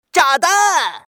zhadanchuxian.mp3